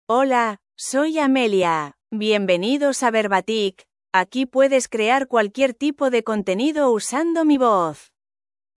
FemaleSpanish (Spain)
AmeliaFemale Spanish AI voice
Amelia is a female AI voice for Spanish (Spain).
Voice sample
Amelia delivers clear pronunciation with authentic Spain Spanish intonation, making your content sound professionally produced.